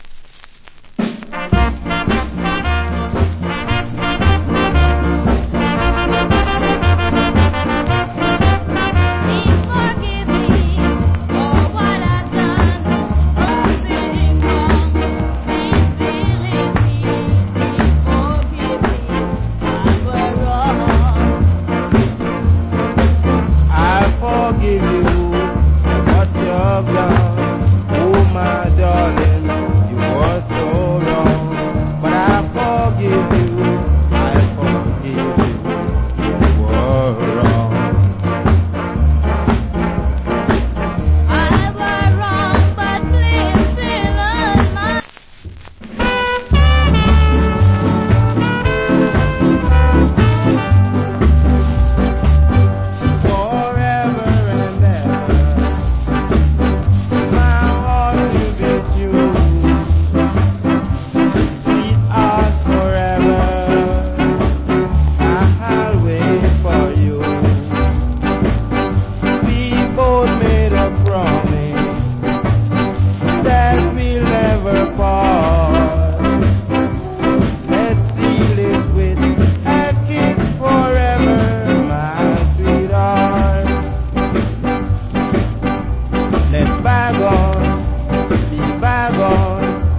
Great Duet Ska Vocal.